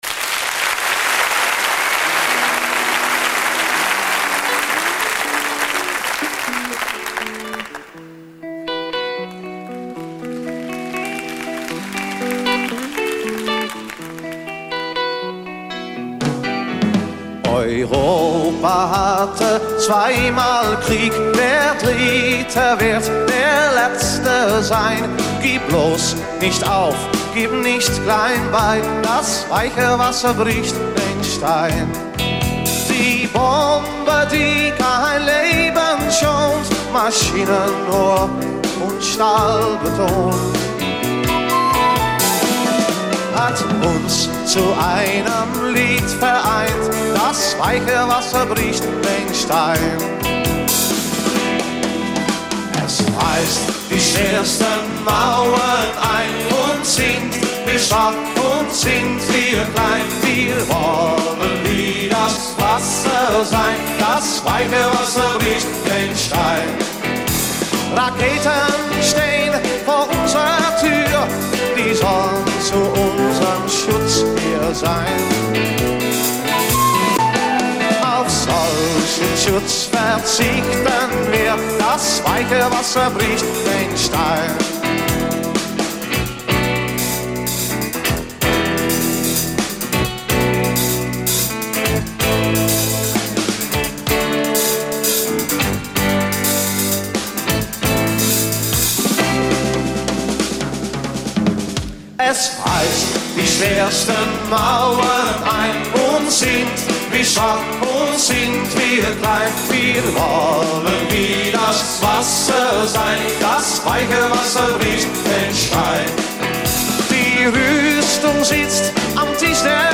niederländischen Musikgruppe